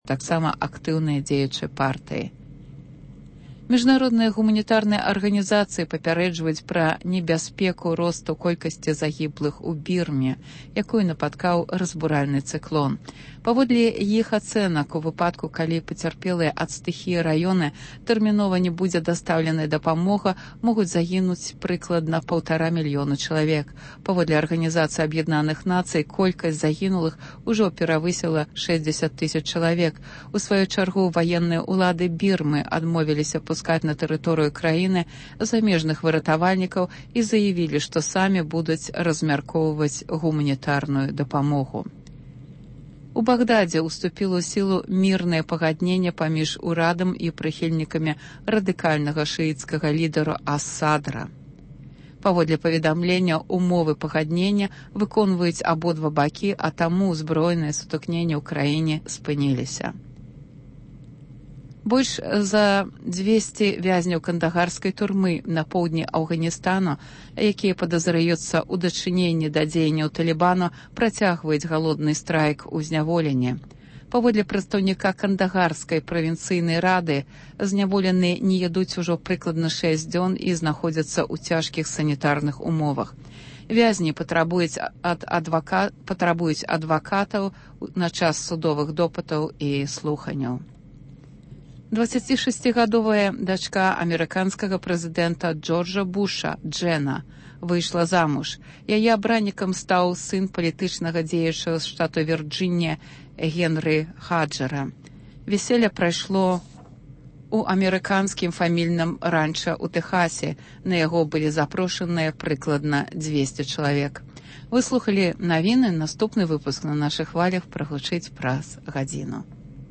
Што азначаюць лібэральныя заявы новага прэзыдэнта, наколькі маюць падставы чаканьні палітычнай “адлігі” пры новым прэзыдэнце? Чаму першы візыт Мядзьведзева – у Казахстан і Кітай, а не ў Беларусь? Над гэтымі пытаньнямі ў “Праскім акцэнце” разважаюць: